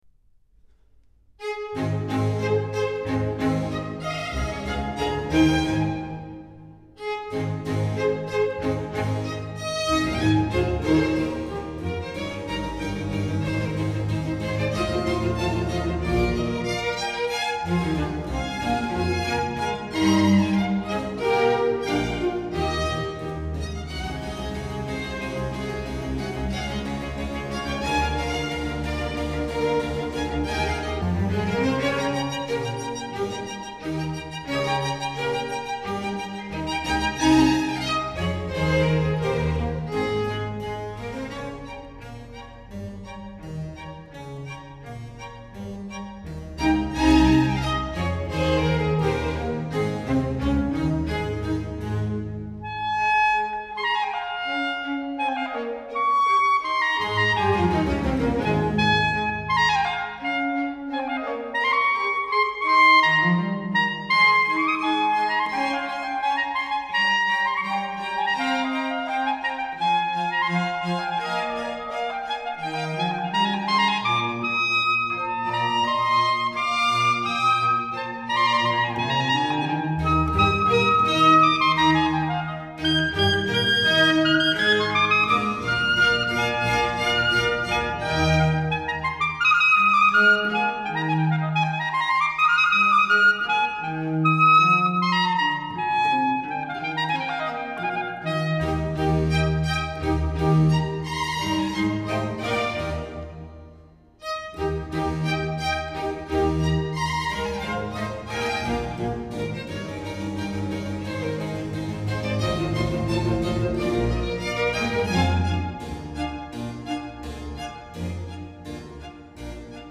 Concerto for clarinet and small orchestra No.1 A major